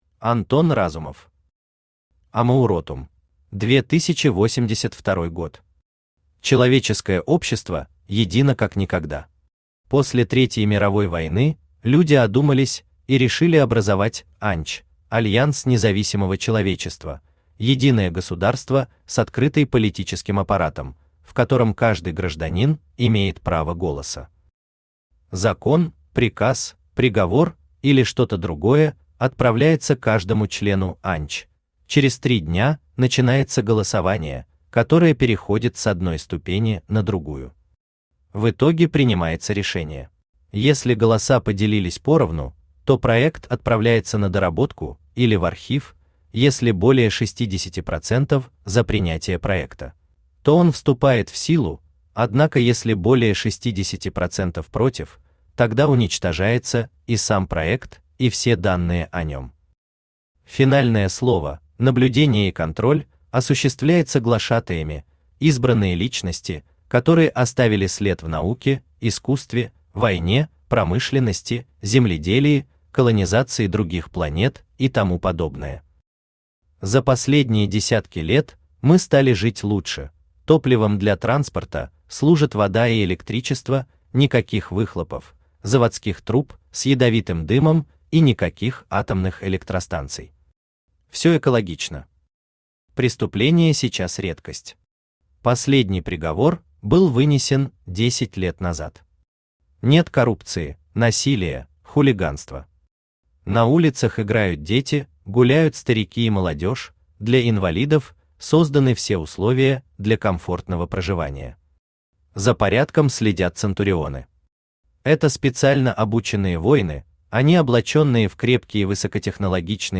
Аудиокнига Амауротум | Библиотека аудиокниг
Aудиокнига Амауротум Автор Антон Андреевич Разумов Читает аудиокнигу Авточтец ЛитРес.